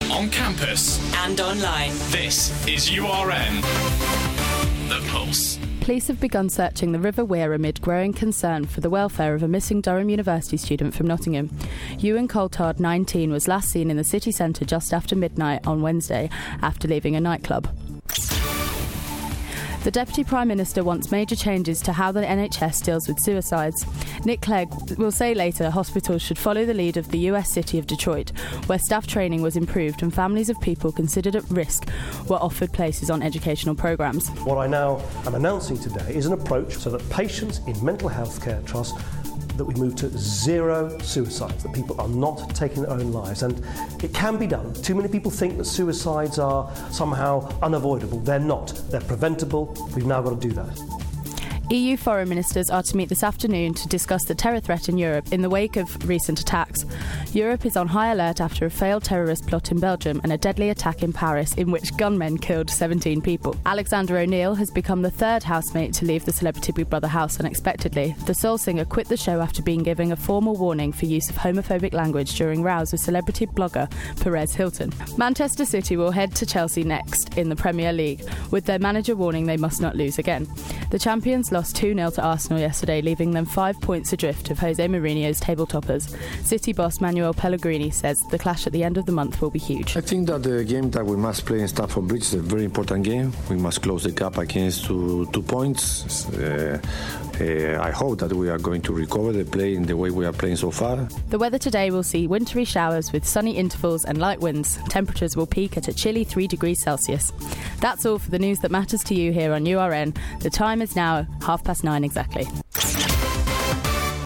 Your Latest Headlines - Monday 19th January